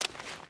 Footsteps / Water / Water Run 1.wav
Water Run 1.wav